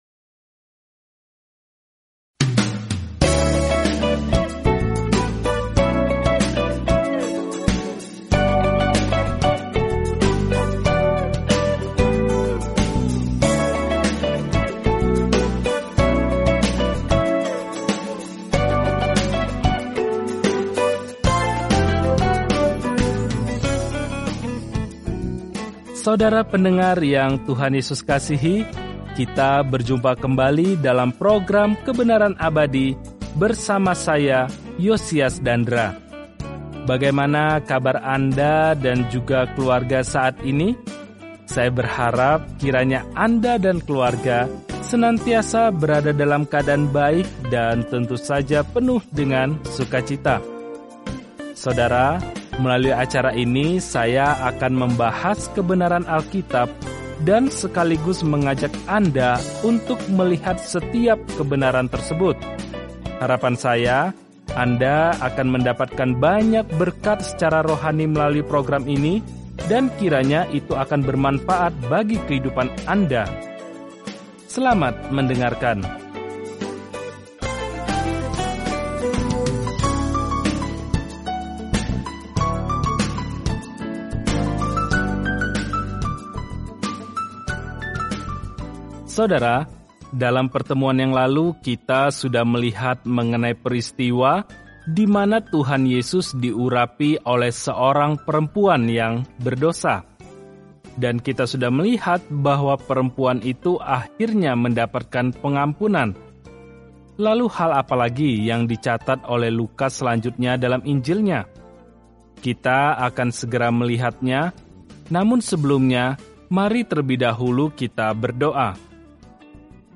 Firman Tuhan, Alkitab Lukas 8 Hari 10 Mulai Rencana ini Hari 12 Tentang Rencana ini Para saksi mata menginformasikan kabar baik yang diceritakan Lukas tentang kisah Yesus sejak lahir, mati, hingga kebangkitan; Lukas juga menceritakan kembali ajaran-Nya yang mengubah dunia. Telusuri Lukas setiap hari sambil mendengarkan pelajaran audio dan membaca ayat-ayat tertentu dari firman Tuhan.